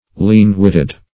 Lean-witted \Lean"-wit`ted\, a. Having but little sense or shrewdness.